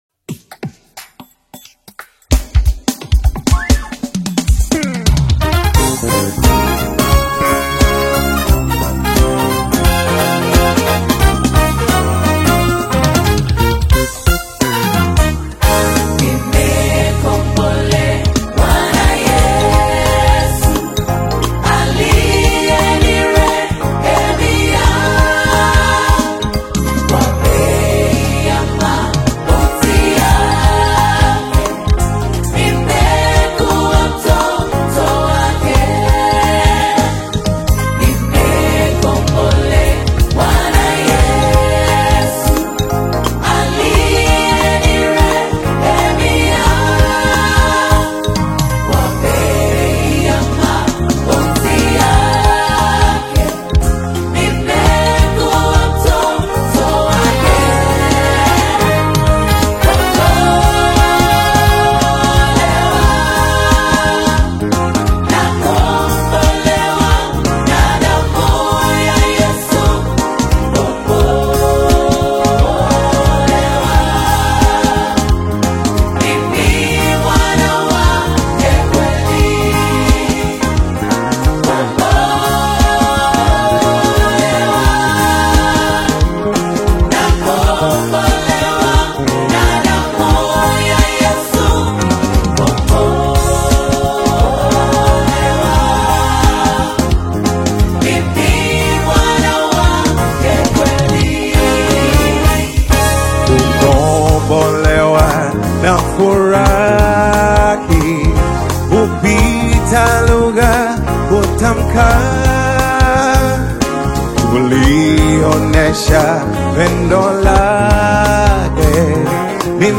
is an uplifting Tanzanian gospel live recording
vibrant live harmonies